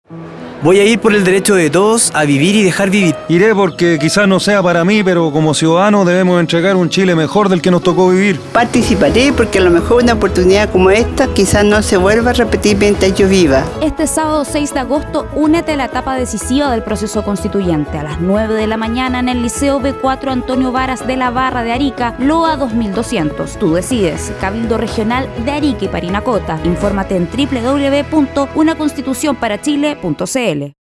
Audio promoción testimonial cabildos regionales, Región de Arica y Parinacota 3